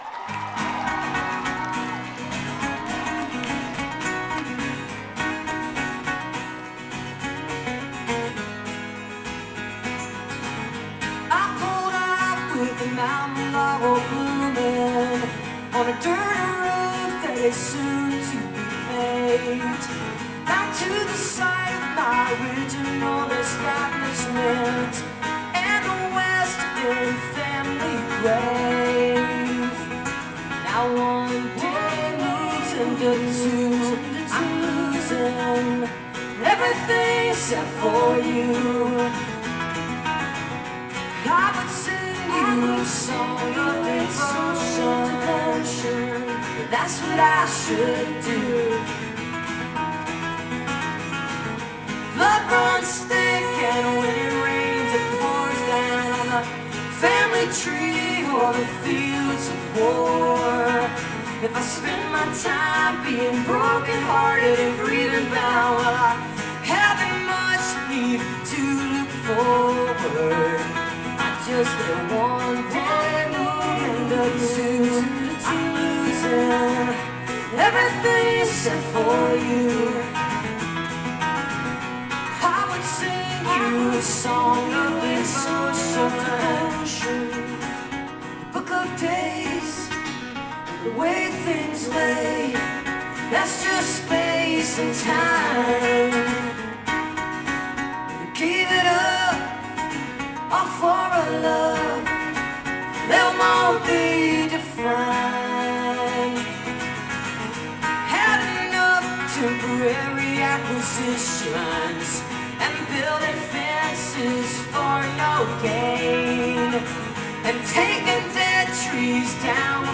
(acoustic duo)